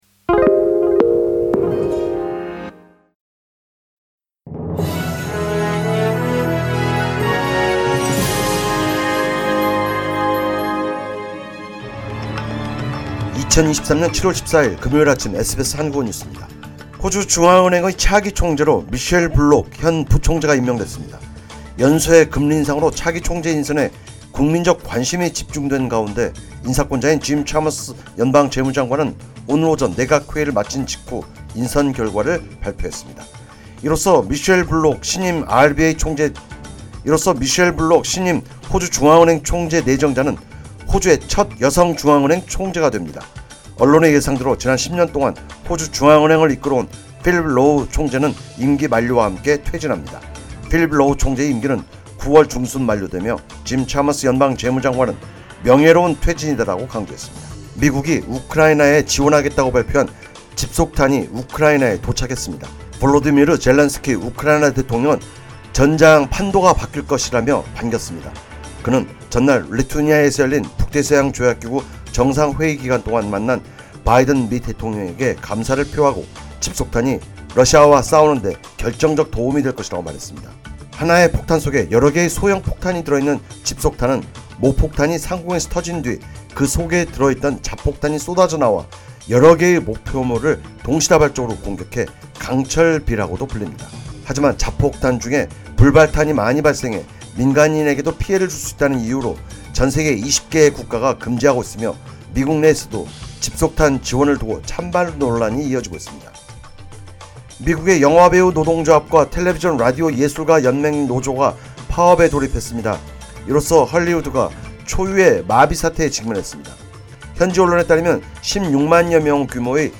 SBS 한국어 아침 뉴스: 2023년 7월 14일 금요일
2023년 7월 14일 금요일 아침 SBS 한국어 뉴스입니다.